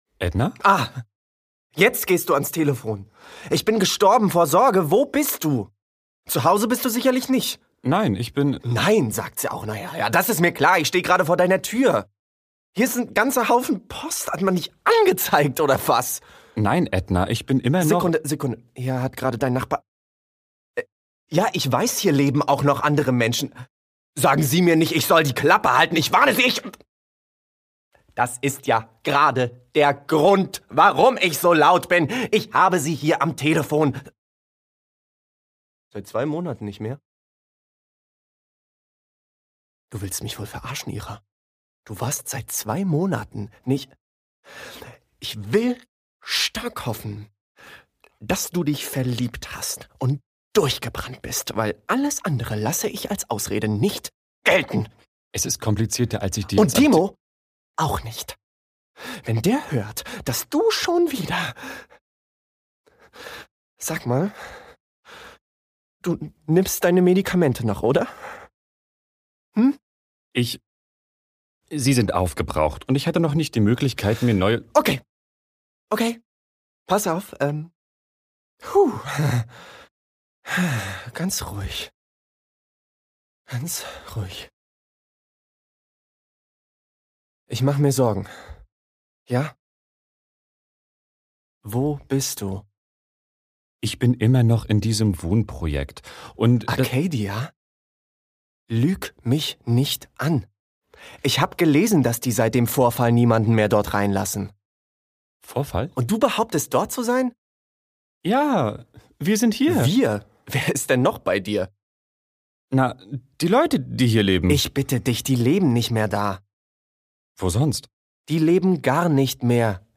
Werbung - MagX Werbung - Patronate Werbung - SportNext Werbung - BeB Werbung - Wasser Voiceover Audioguide Erklärfilm Trickstimmen